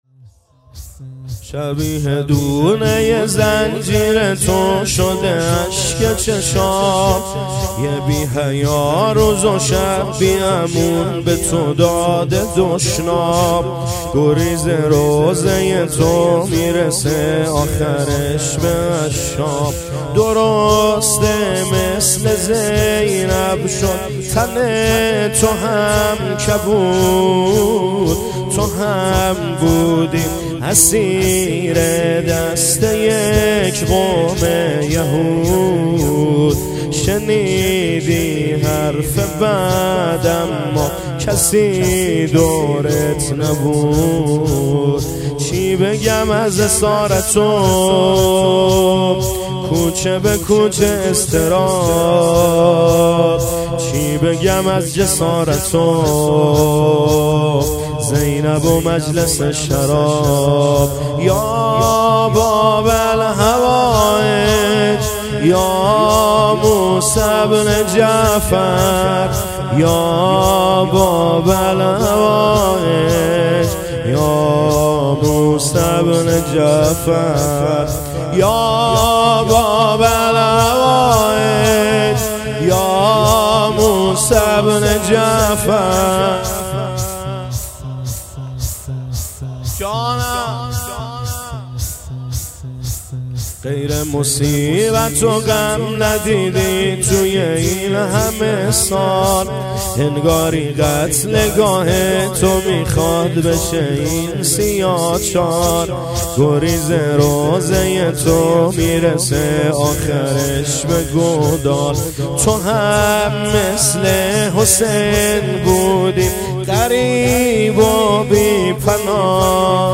زمینه - شبیه دونه زنجیر تو
عزاداری شهادت امام کاظم علیه السلام - وفات حضرت ابوطالب - ‌پنجشنبــه ۲۷ بهمن ماه ۱۴۰۱